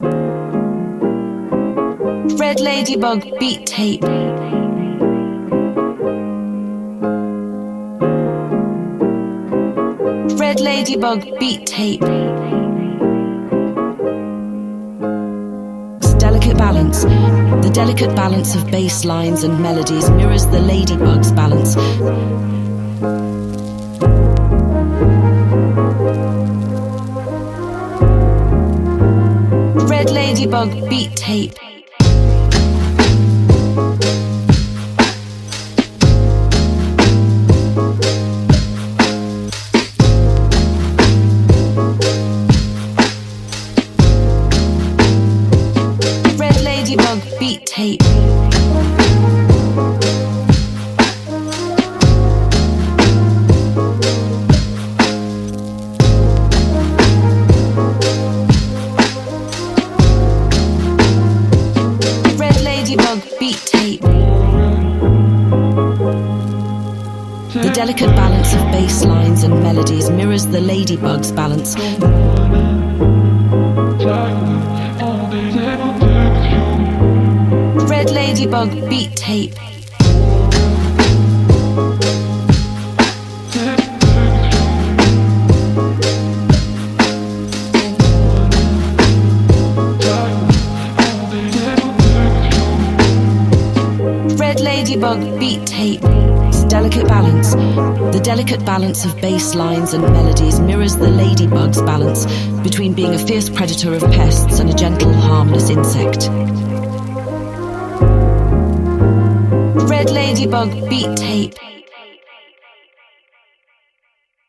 2. Boom Bap Instrumentals